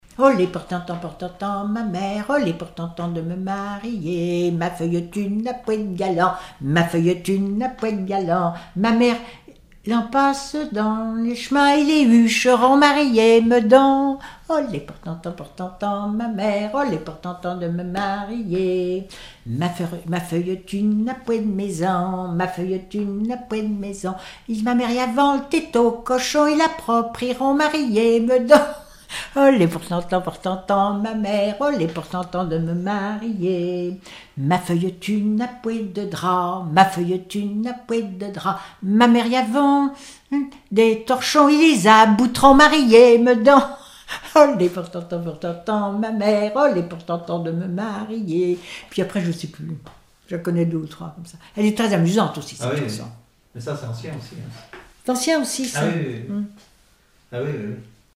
Genre dialogue
Chansons et témoignages
Pièce musicale inédite